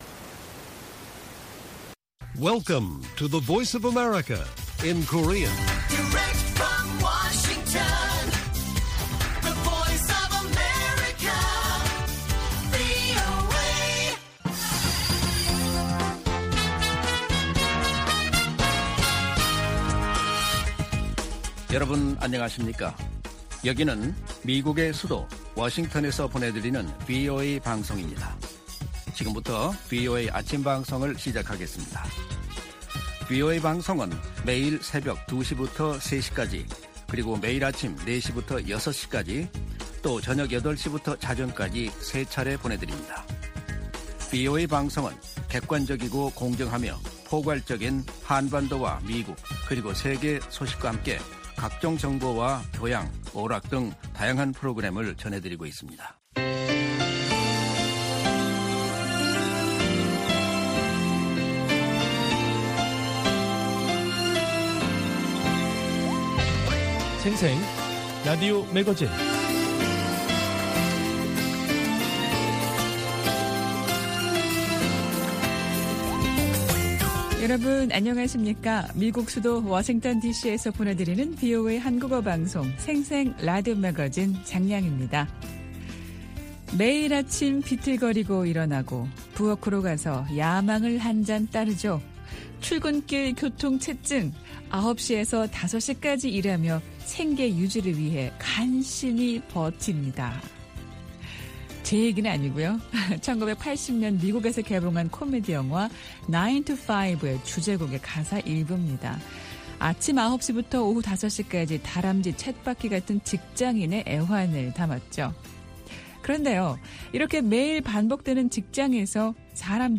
VOA 한국어 방송의 월요일 오전 프로그램 1부입니다. 한반도 시간 오전 4:00 부터 5:00 까지 방송됩니다.